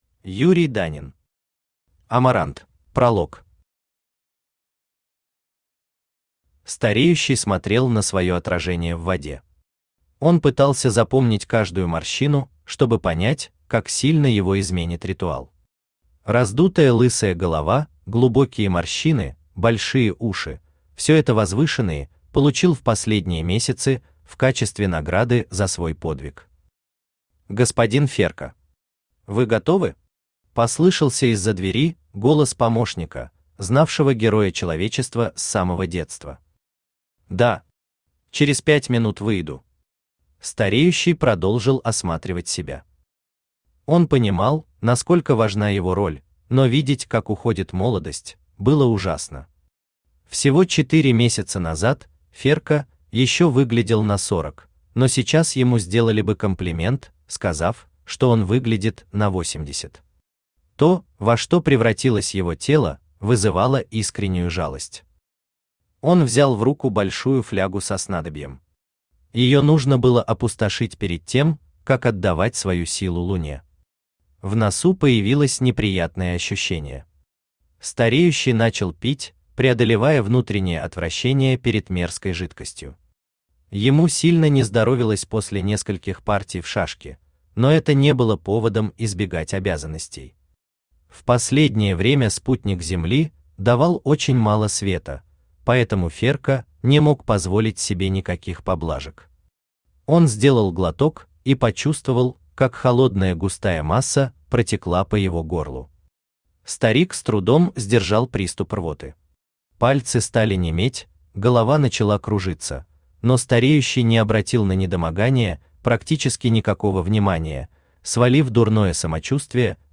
Aудиокнига Амарант Автор Юрий Данин Читает аудиокнигу Авточтец ЛитРес.